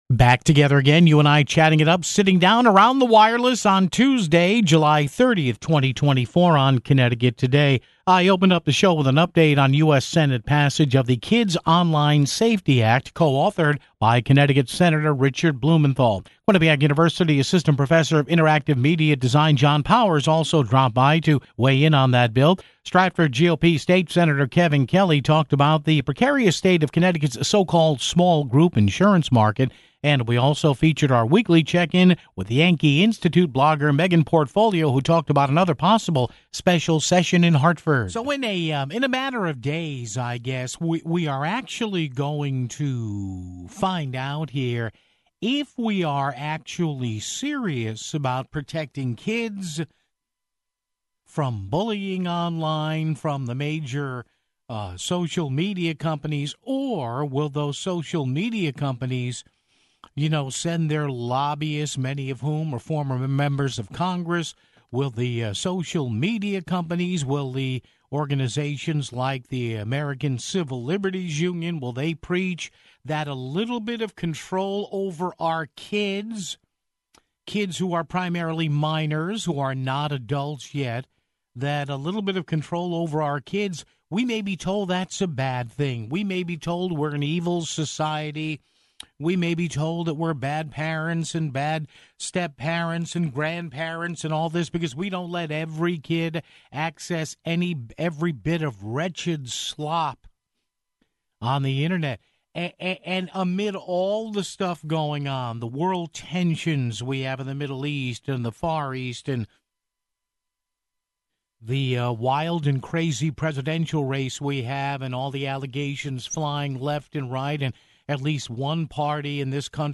Stratford GOP State Sen. Kevin Kelly talked about the precarious state of Connecticut's "small group" insurance market (23:13).